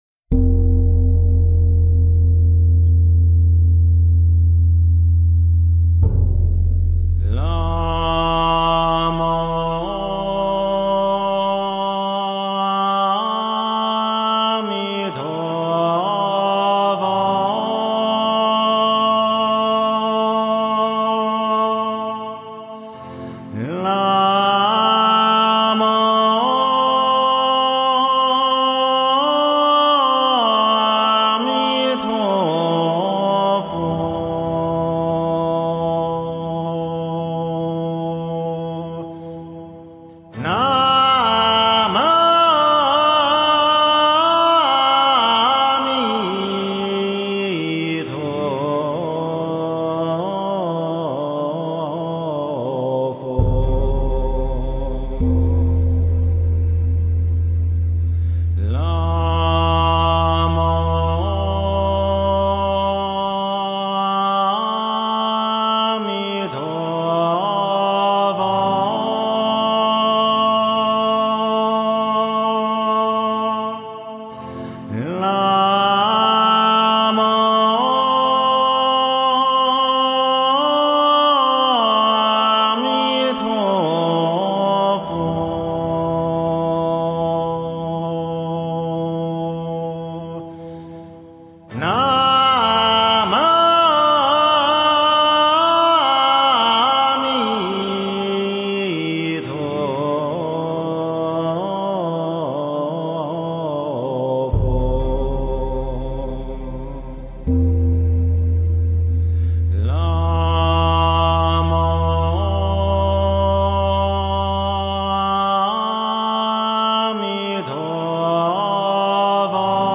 诵经
佛音 诵经 佛教音乐 返回列表 上一篇： 密集玛 下一篇： 炉香赞 相关文章 阿弥陀佛赞--佛教音乐 阿弥陀佛赞--佛教音乐...